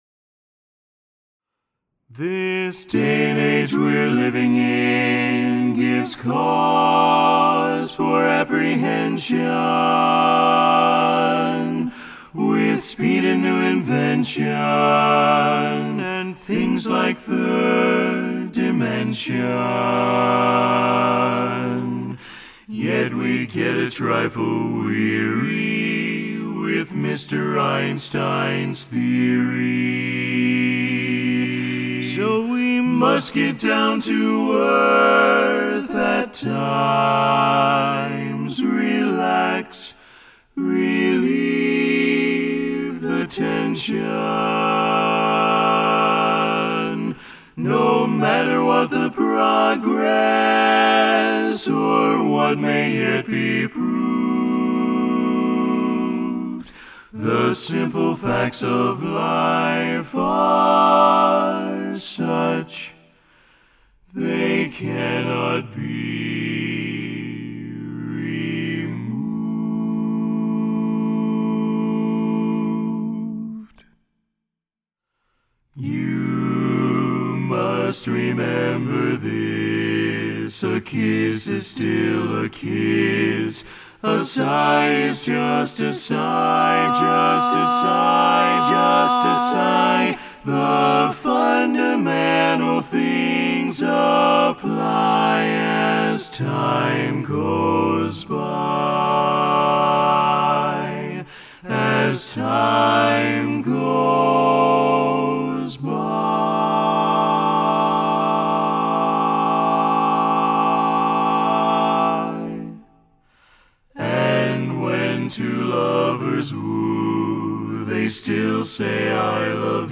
Kanawha Kordsmen (chorus)
D Major